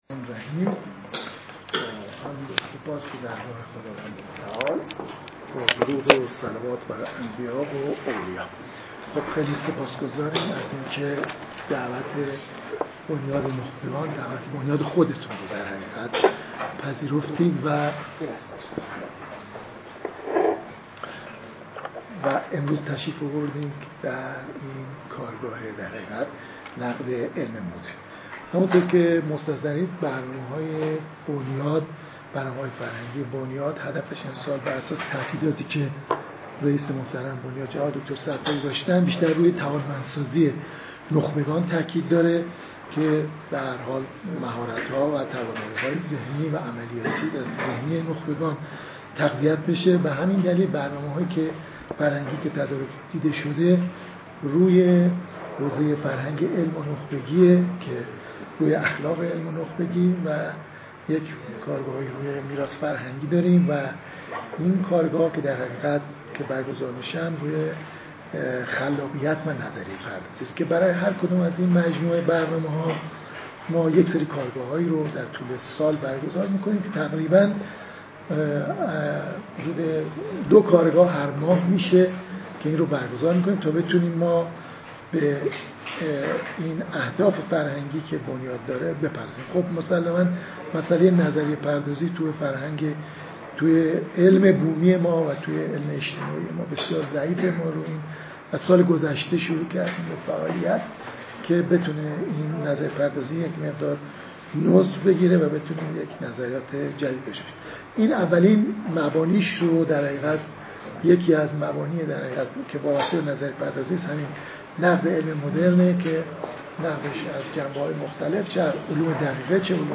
کارگاه آموزشی